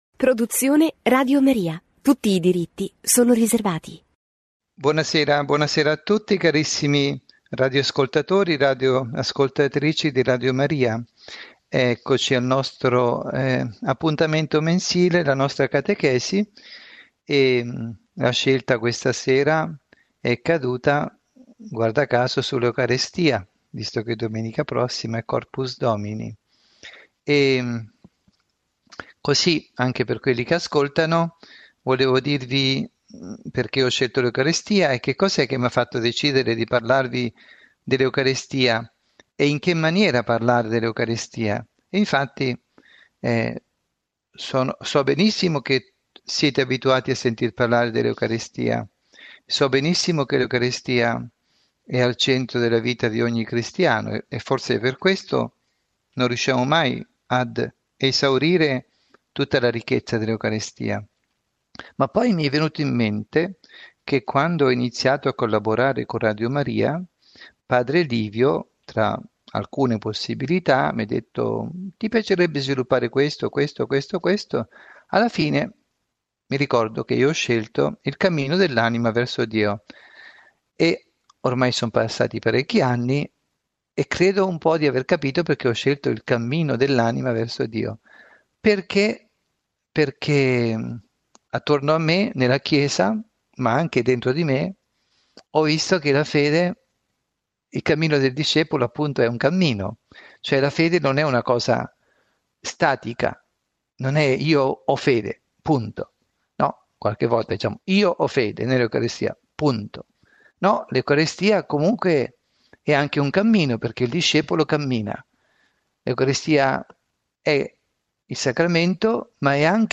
Catechesi